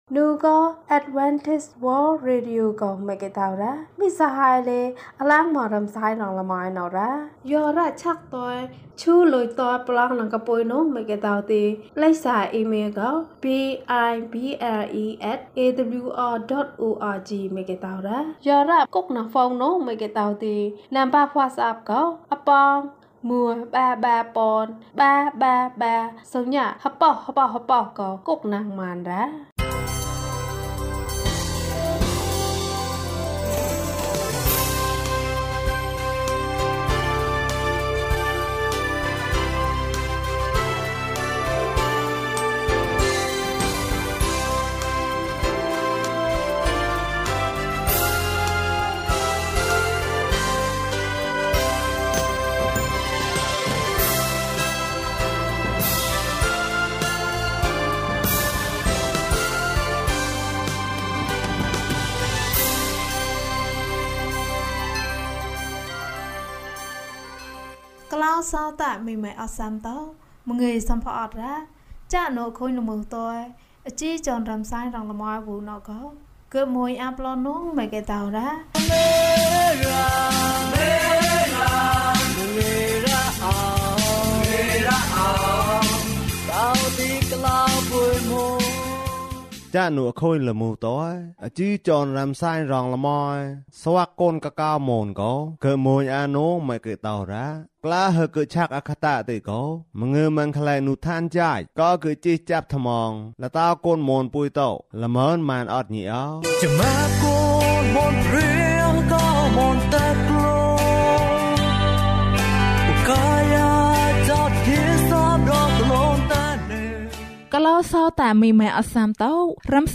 သင့်မိသားစုကိုကူညီပါ။ ကျန်းမာခြင်းအကြောင်းအရာ။ ဓမ္မသီချင်း။ တရားဒေသနာ။